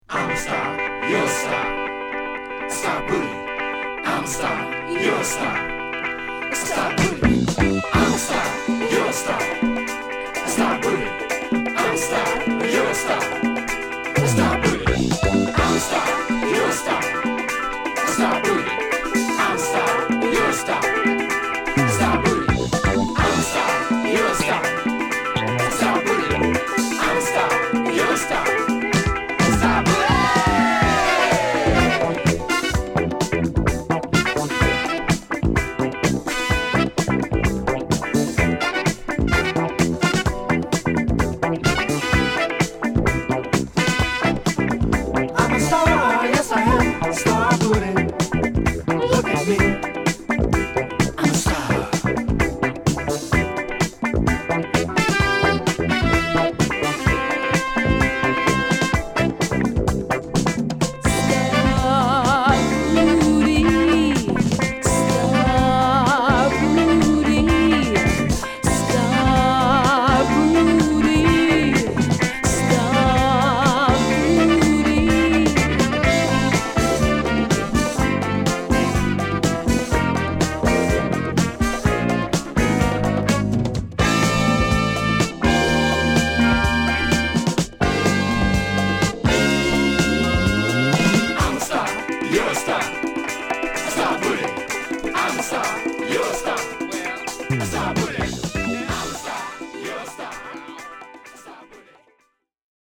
カッティングにパーカッション、シンセベースにエレピや軽快なホーンが絡み、ファンク度高く仕上げたディスコファンクチューン！
Stereo